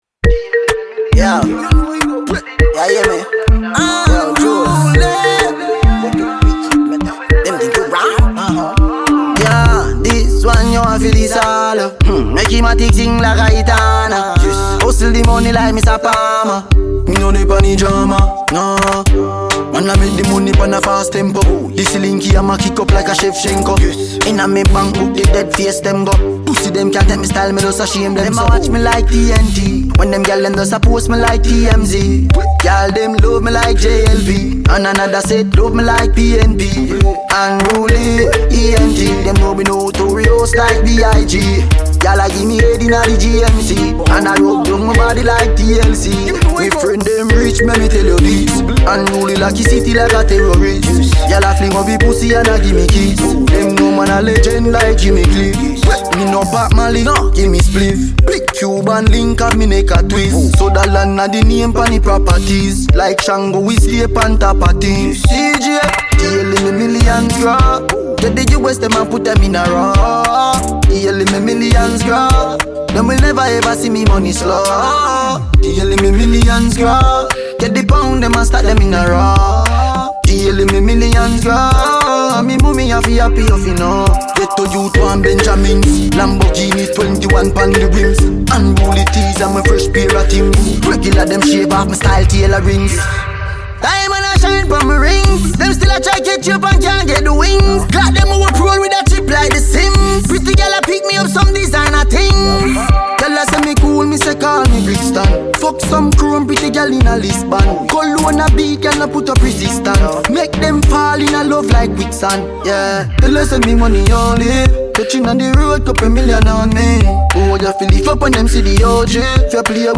Dancehall/HiphopMusic